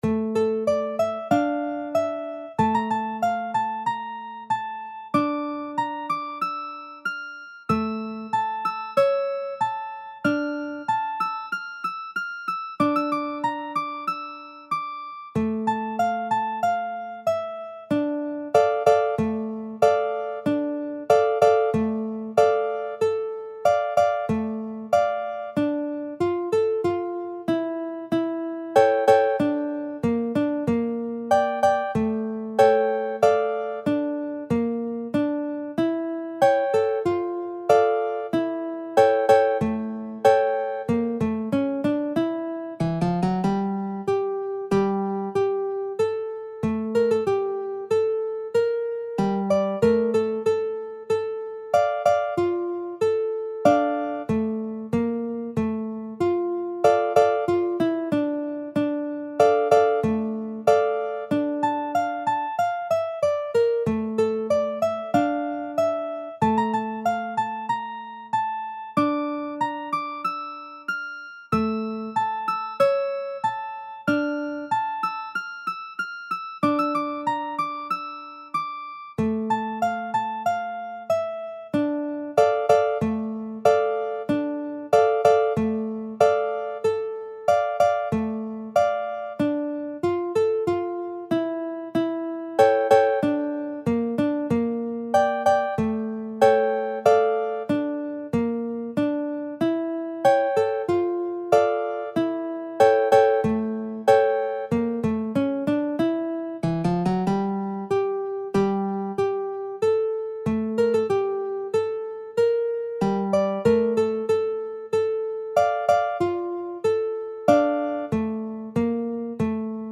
歌謡曲・演歌
楽譜の音源（デモ演奏）は下記URLよりご確認いただけます。
（この音源はコンピューターによる演奏ですが、実際に人が演奏することで、さらに表現豊かで魅力的なサウンドになります！）